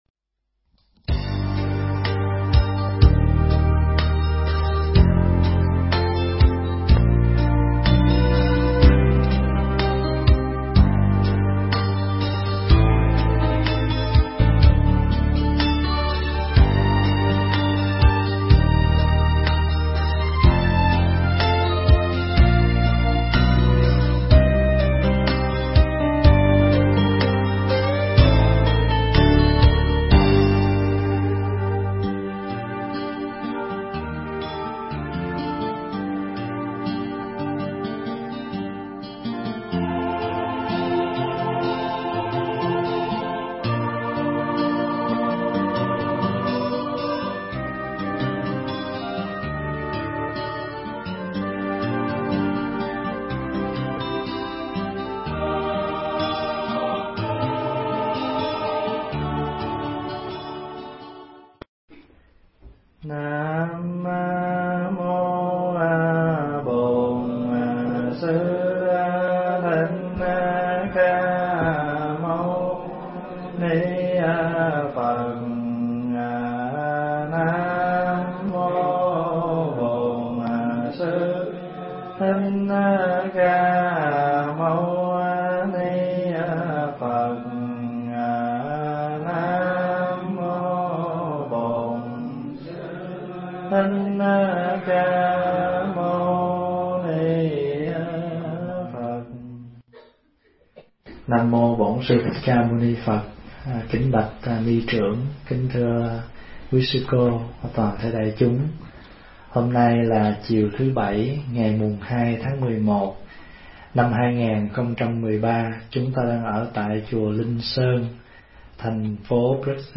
thuyết giảng tại Chùa Linh Sơn, Brussels, Bỉ